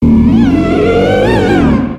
Cri de Banshitrouye Taille Ultra dans Pokémon X et Y.
Cri_0711_Ultra_XY.ogg